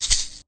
cf_shake.ogg